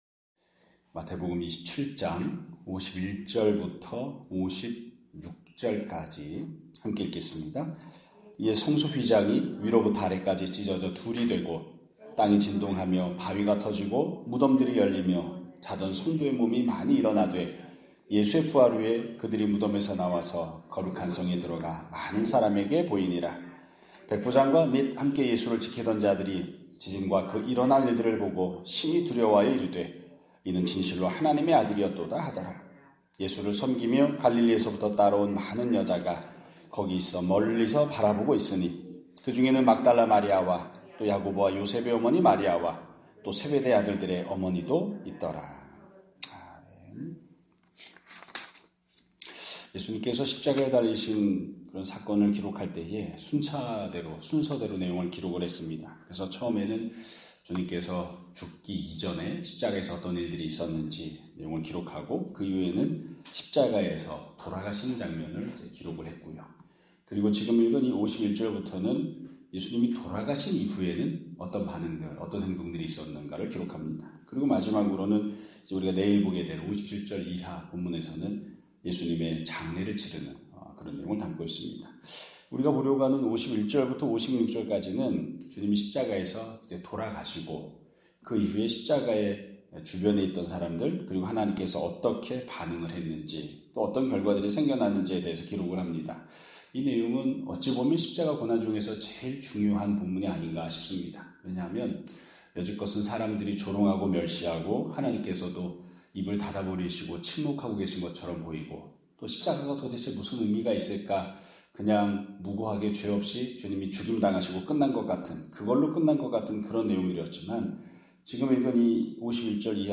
2026년 4월 23일 (목요일) <아침예배> 설교입니다.